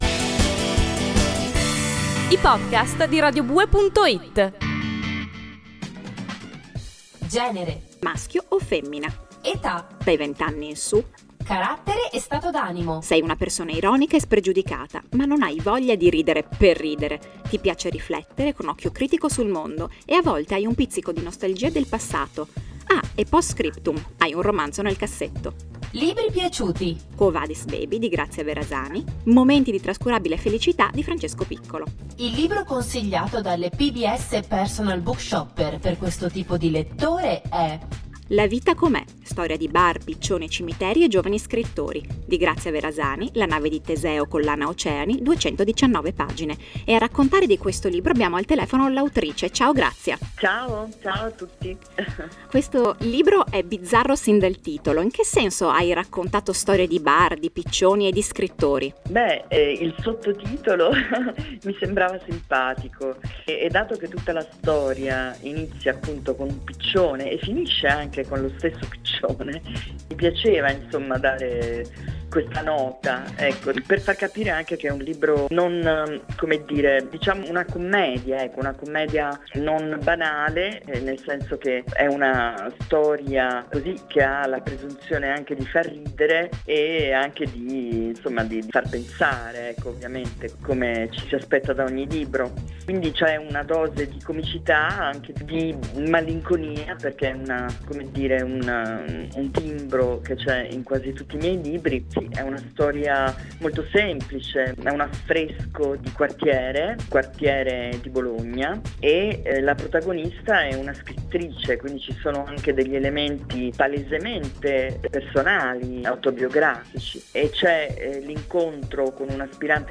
La vita com’è, intervista a Grazia Verasani